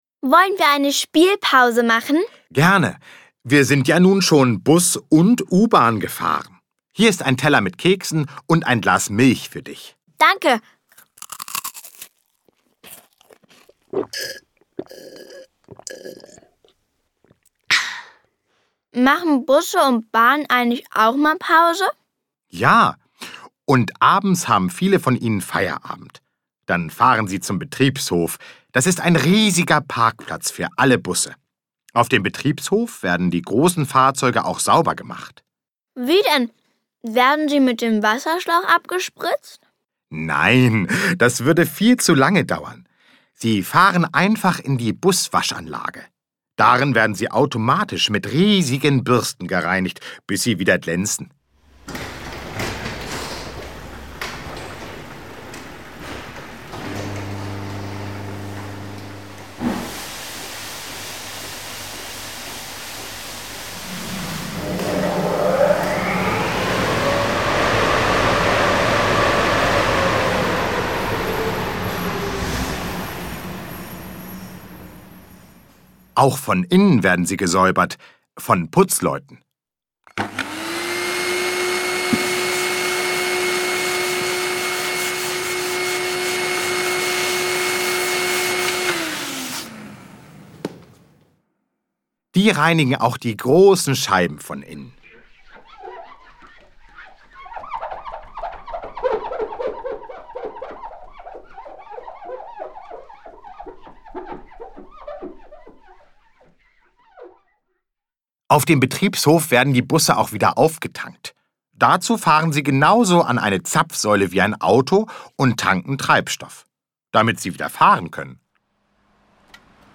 Im Hörspiel erfahren sie spielerisch alles, was sie als Fahrgäste wissen müssen und lernen die Regeln, die in Bus und Bahn gelten: Beim Fahren müssen sich alle gut festhalten und wer aussteigen möchte, muss im Bus auf den Halteknopf drücken. Mit lustigen Merkversen und dem bekannten Mitmachlied "Die Räder vom Bus" macht die Fahrt mit Bus und Bahn noch mehr Spaß. Altersgerechte Fragen und Antworten, authentische Geräusche, viele Mitmach-Aktionen und Musik machen die Reihe "Wieso? Weshalb? Warum? junior" zu einem Hörerlebnis für die Kleinsten.